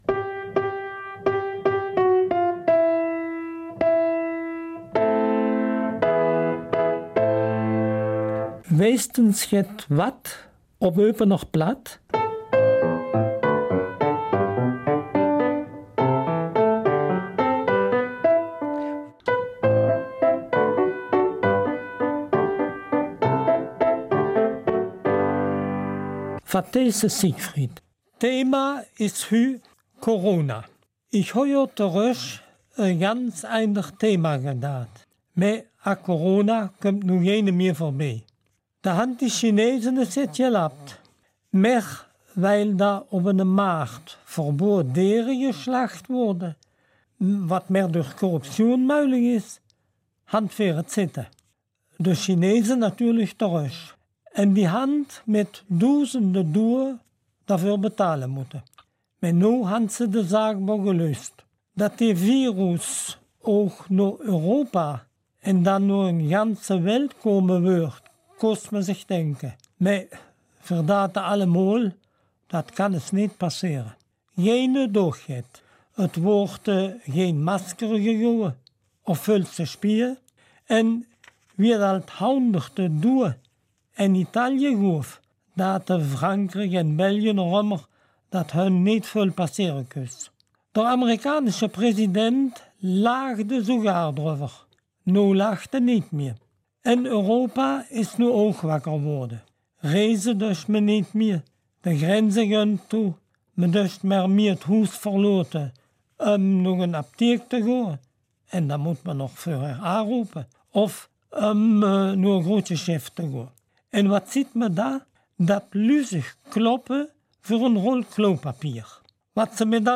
Eupener Mundart - 12. April